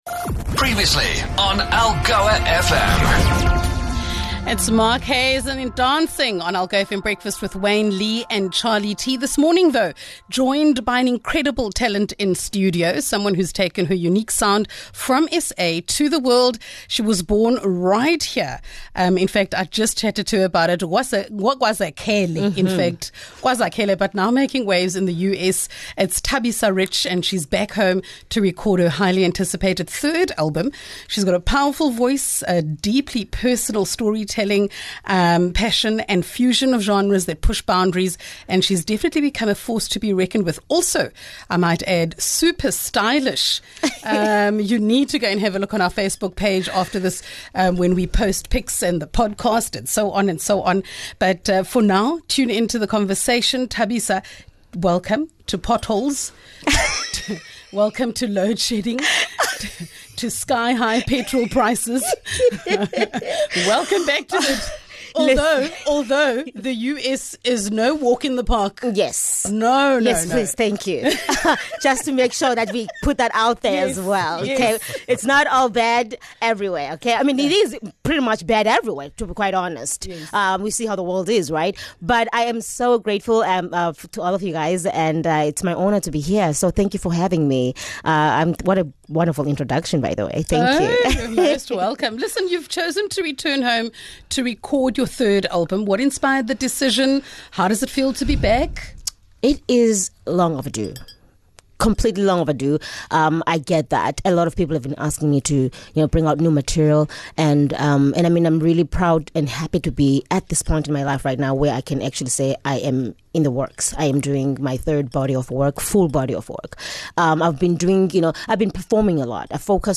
MultiMedia LIVE